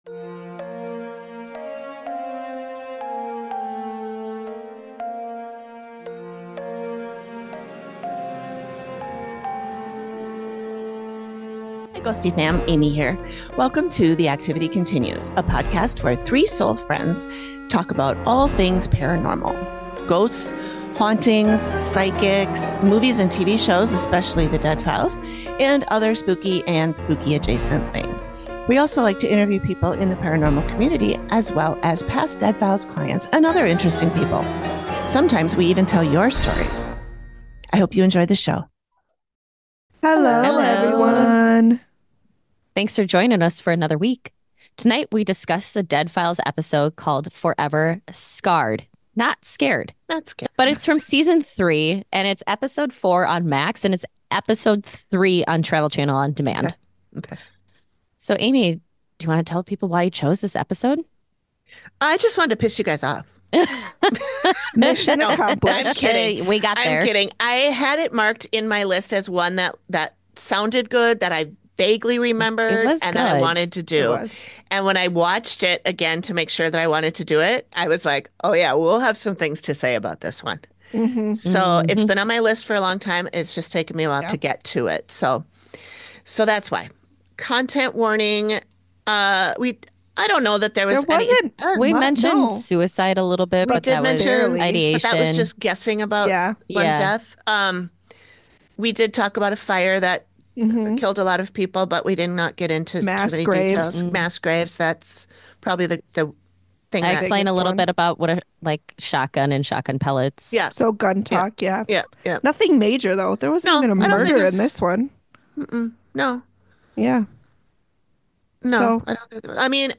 The Activity Continues is a podcast where three soul friends talk about all things paranormal. Ghosts, hauntings, psychics, movies and TV shows (especially The Dead Files) and other spooky and spooky-adjacent things as well.